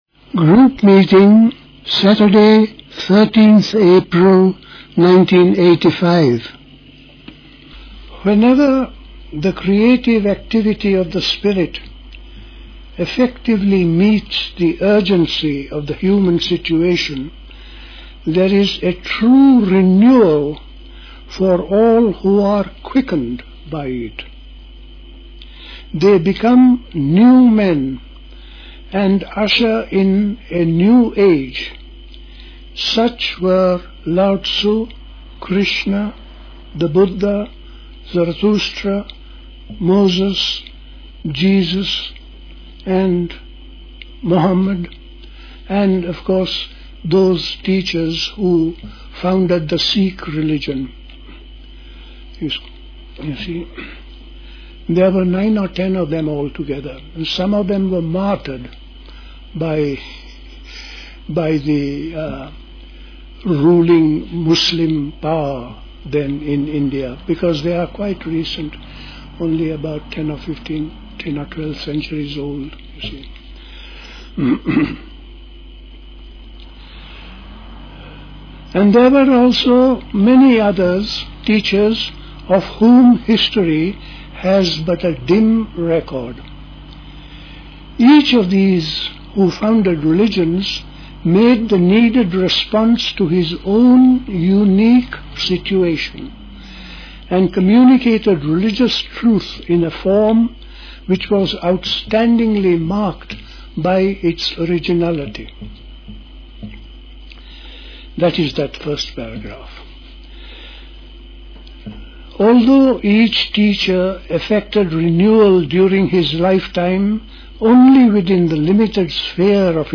A talk
at Dilkusha, Forest Hill, London on 13th April 1985